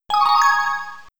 Écoutons d’abord les sons joués lorsque le joueur s’empare d’une ressource sur la carte.
Certains sons, plus stridents font penser à une pluie d’or, tandis que d’autres ont une profondeur (grâce à la réverbération et la longueur du son) que l’on ne peut trouver que dans certaines pierres précieuses ; certains font penser à une forme brute et anguleuse (avec une attaque très marquée) tandis que d’autres ont une forme de rondeur et de douceur qui peuvent évoquer la perle.